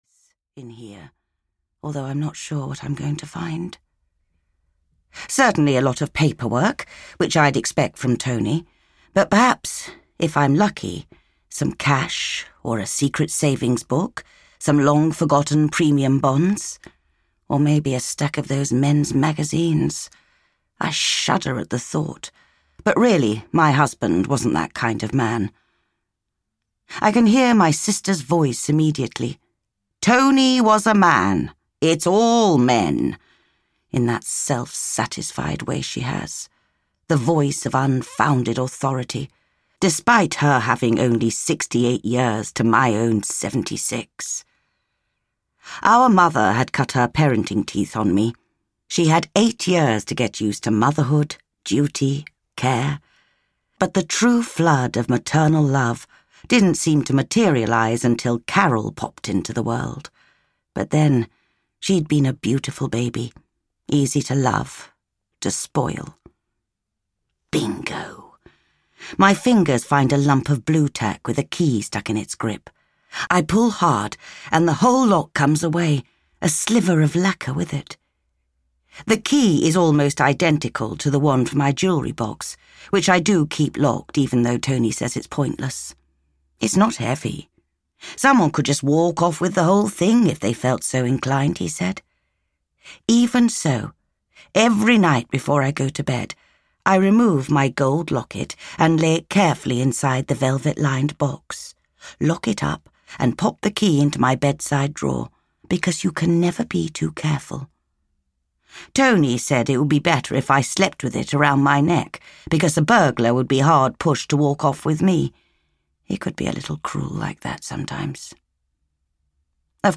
The Second Chance Holiday Club (EN) audiokniha
Ukázka z knihy